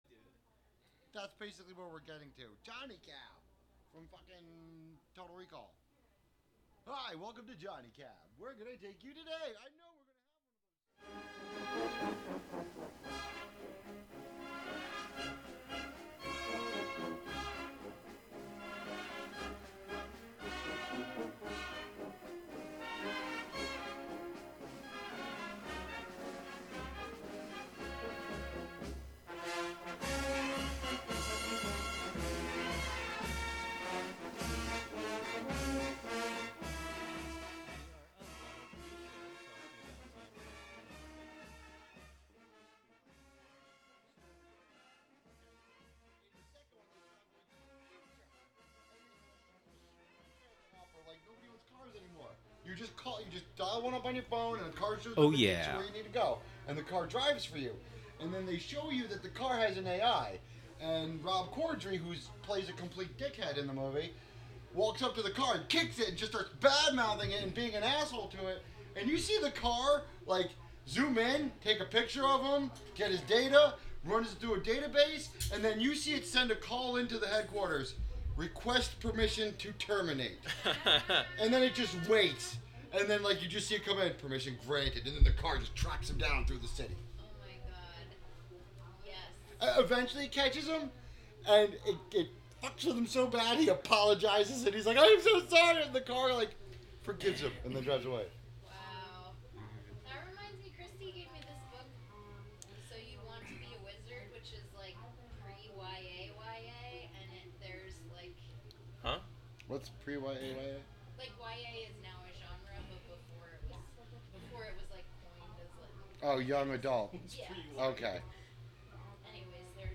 Live from the Thingularity Studios